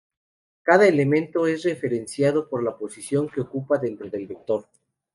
Read more vector Frequency 24k Hyphenated as vec‧tor Pronounced as (IPA) /beɡˈtoɾ/ Etymology Borrowed from Latin vector In summary Learned borrowing from Latin vector.